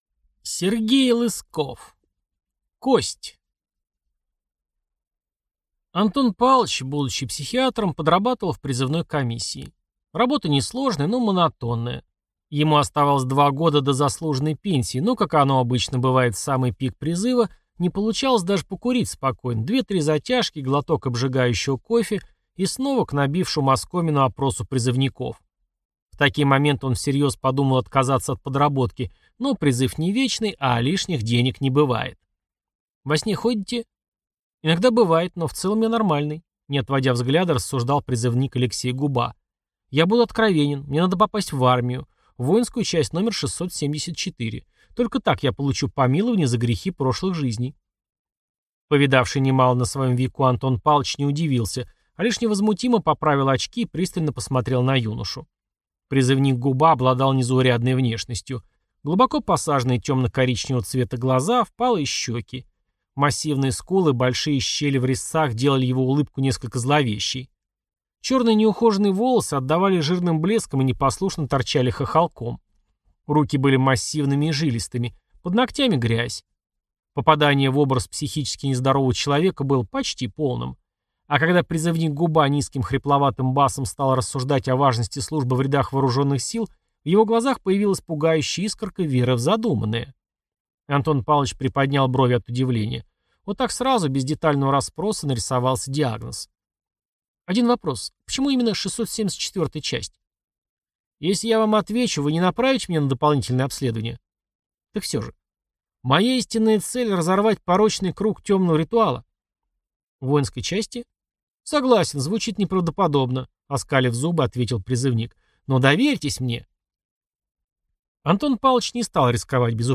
Аудиокнига Кость | Библиотека аудиокниг